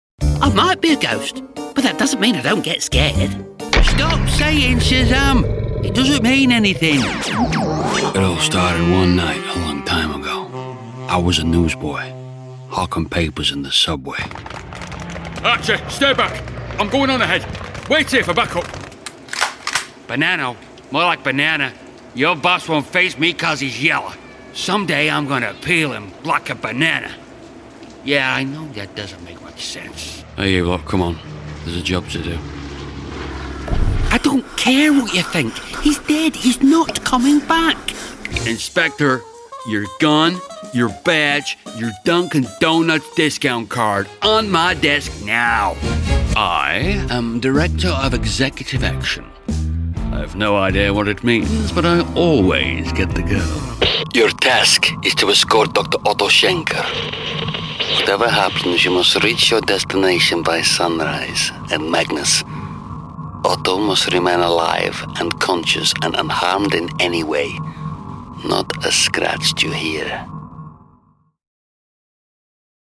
Character Showreel
Down to earth, friendly Northern actor.
Male
Manchester